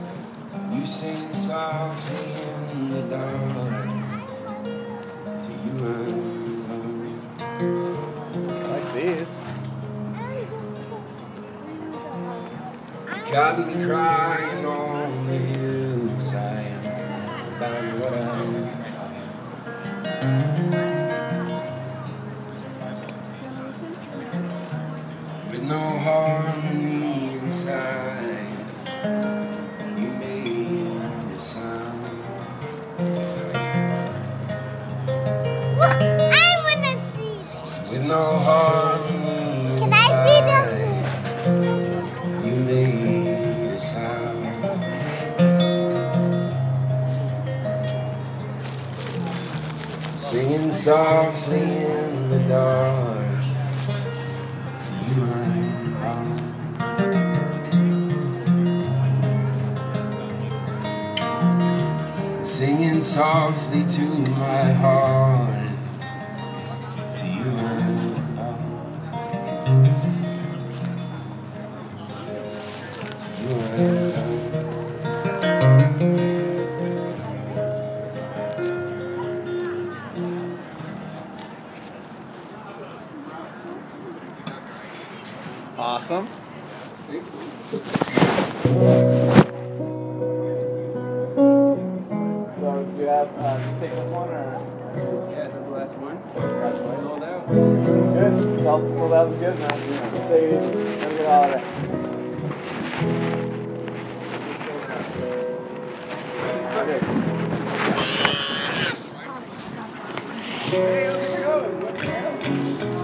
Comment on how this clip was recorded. with me buying CD at end -- sorry